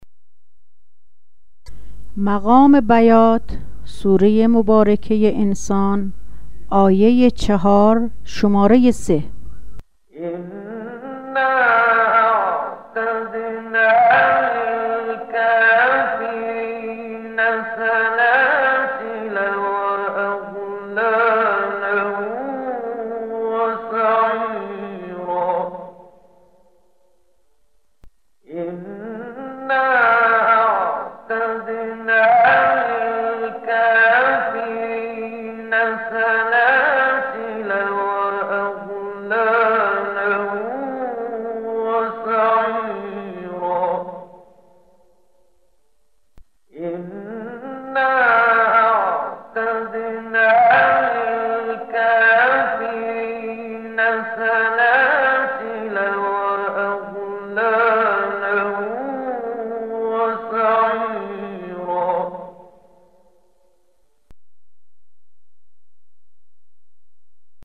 بیات ویژگی منحصر به فرد و به غایت پر سوز و تأثیر گزاری دارد که ضمن ایجاد حزنی خاص در شنونده، وی را به تدبّر دعوت می کند.
🔸آموزش مقام بیات (قرار۳)
👤 با صدای استاد محمد صدیق المنشاوی